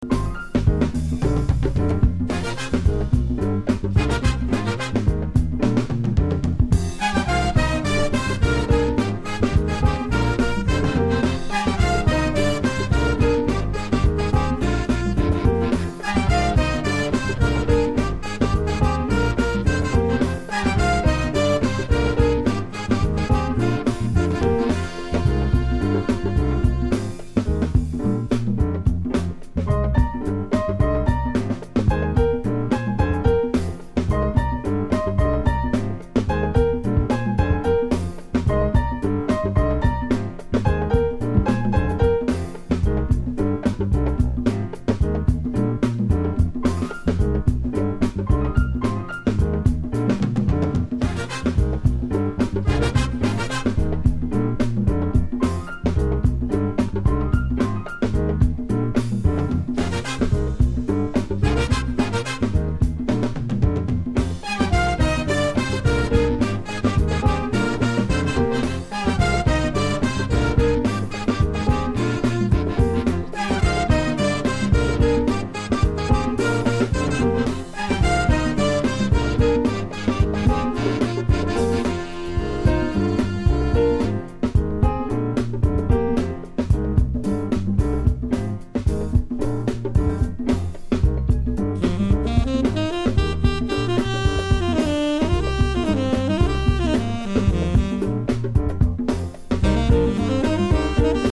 Essential cosmic afro-jazz!